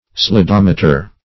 Search Result for " slidometer" : The Collaborative International Dictionary of English v.0.48: Slidometer \Sli*dom"e*ter\, n. [Slide + -meter.]